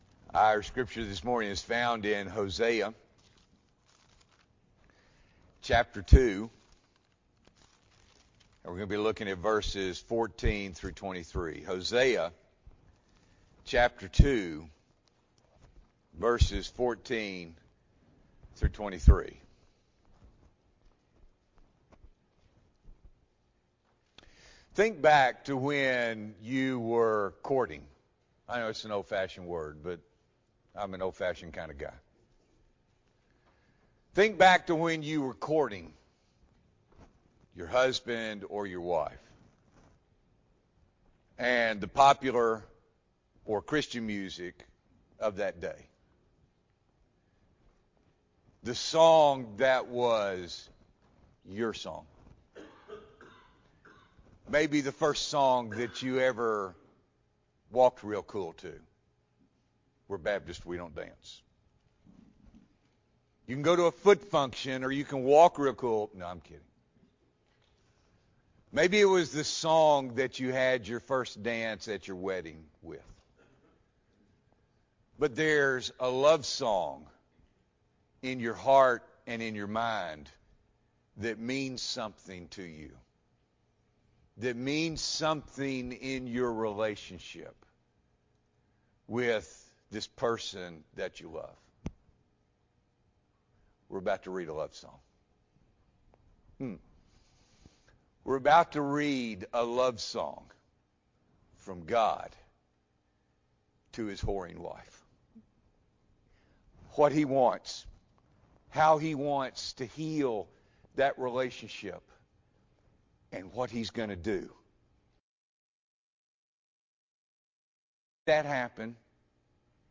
July 24, 2022 – Morning Worship